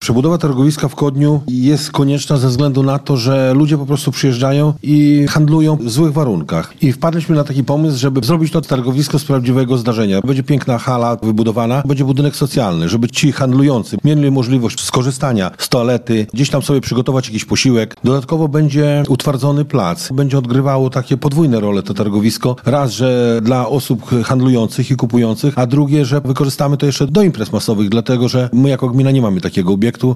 Targowisko wykorzystamy również do imprez masowych, bowiem gmina nie ma posiada odpowiedniego obiektu do ich organizacji – mówi wójt gminy Kodeń, Jerzy Troć.